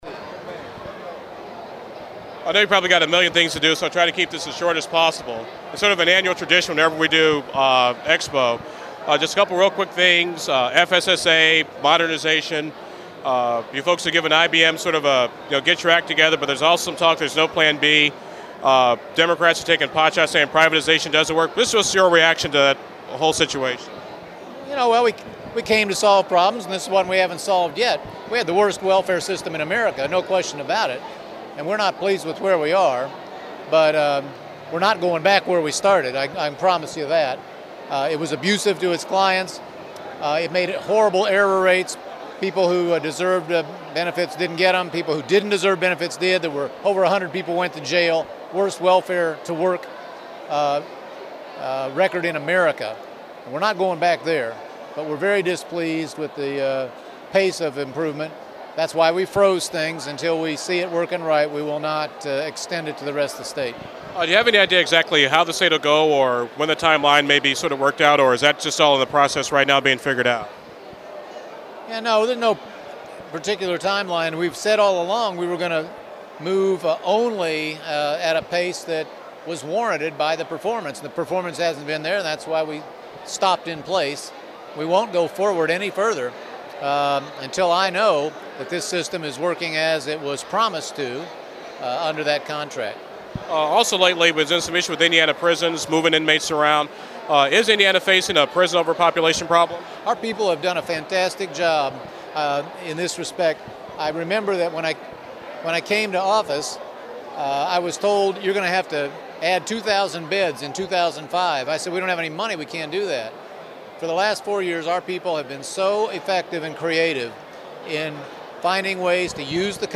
Governor Mitch Daniels and I chatted briefly Tuesday at the Governor’s Reception for Indiana Black Expo.   I asked him about FSSA and the state halting the IBM contract,  changes in Indiana prisons, local government cutbacks and the “Mitch for President” chatter.